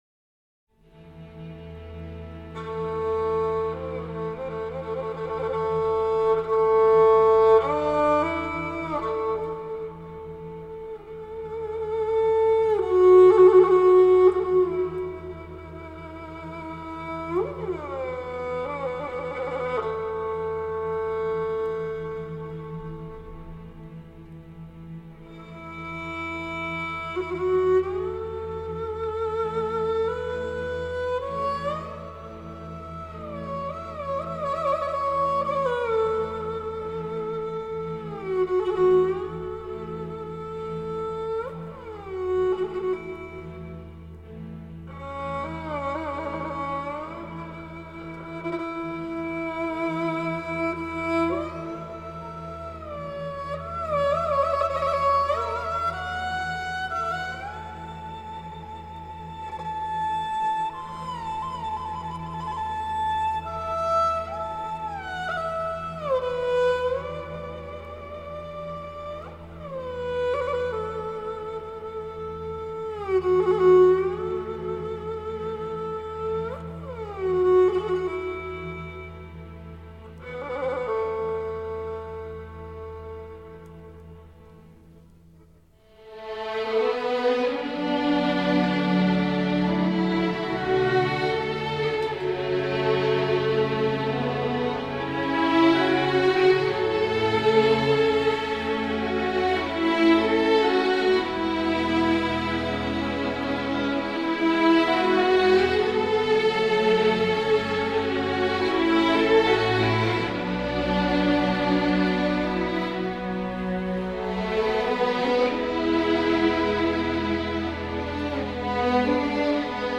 乐曲充分利用西洋管弦乐丰富音色及强大的表现力